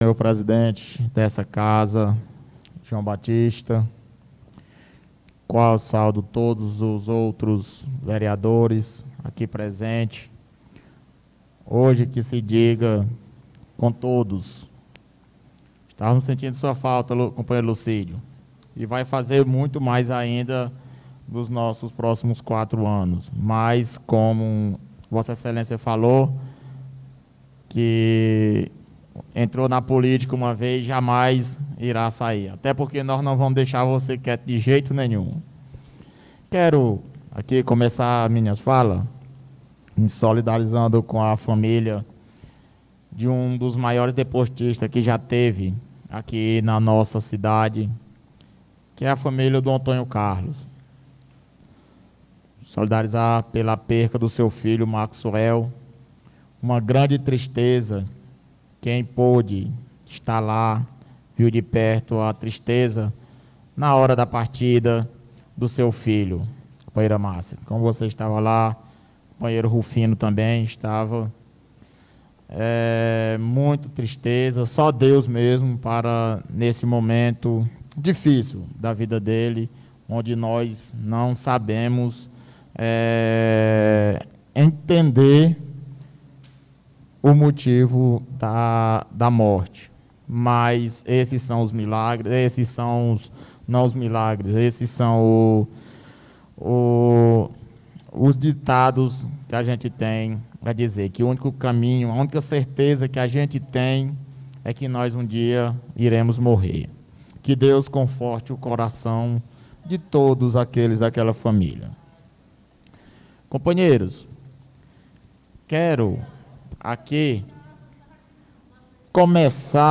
Pronunciamento Ver Neilon Carvalho